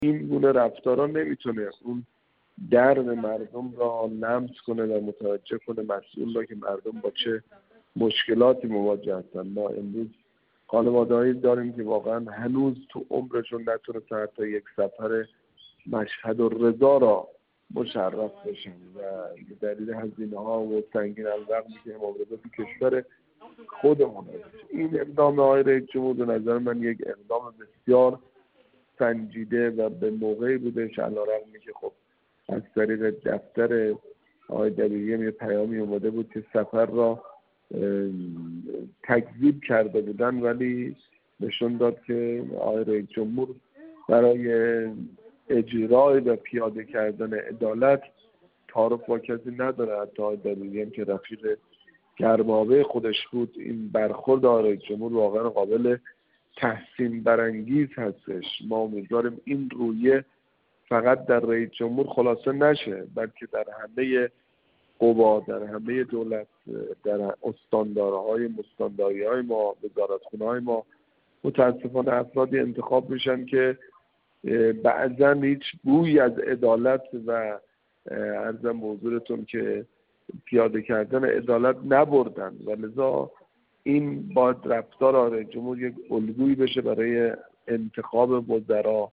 محمدحسن آصفری، کارشناس مسائل سیاسی